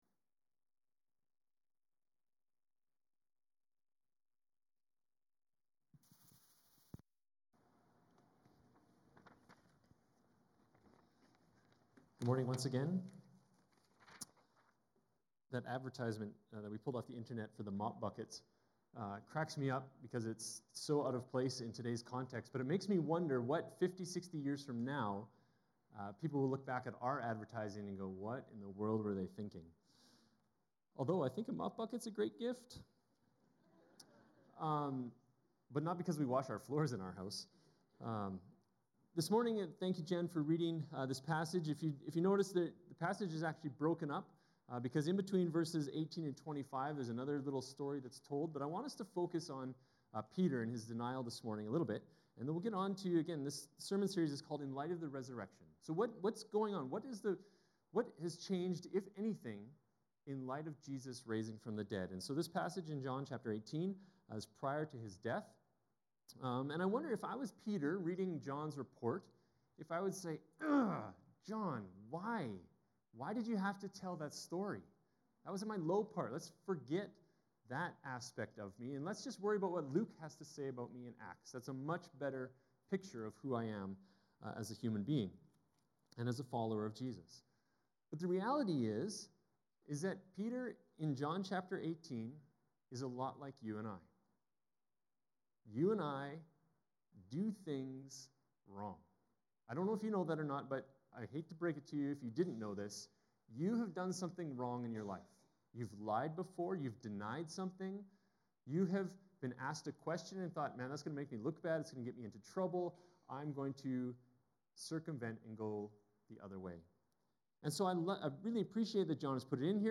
Archived Sermons | Crescent Heights Baptist Church